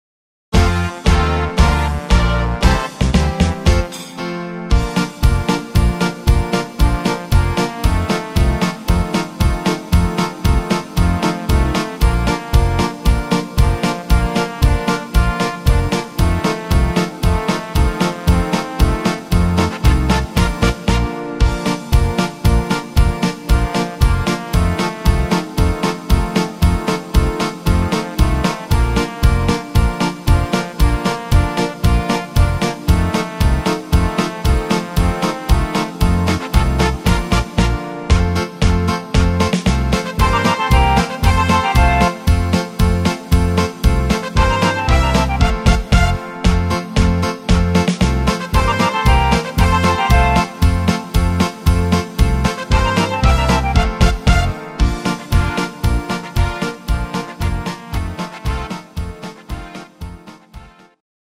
instr. Klarinette